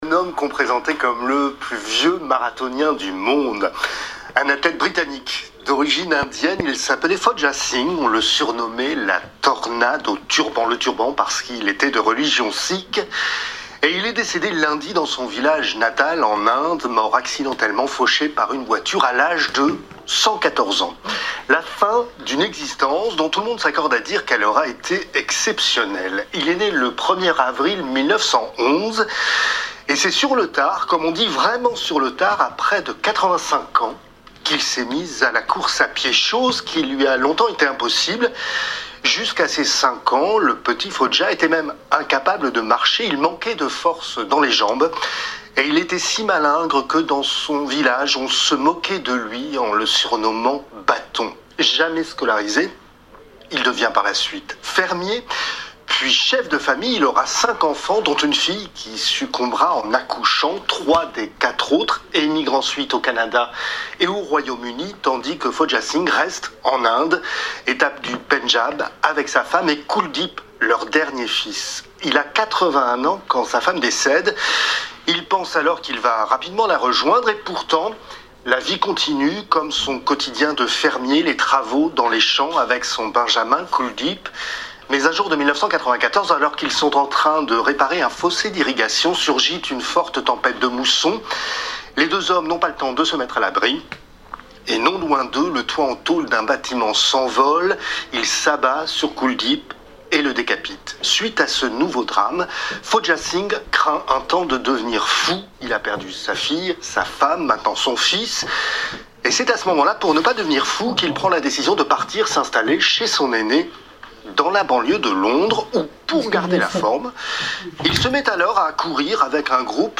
Le discours du roi Philippe pour rappeler les points importants de l'année avec ses commentaires :